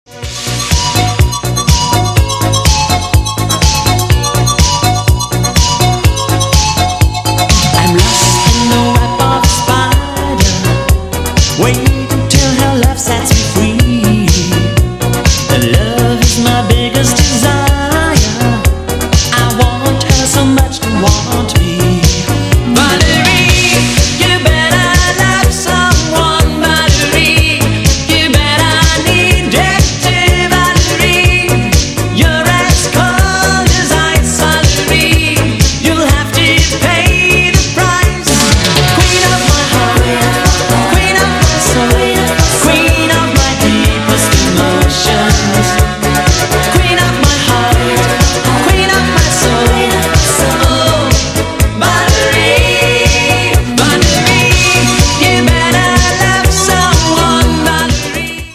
80s Romantic Hit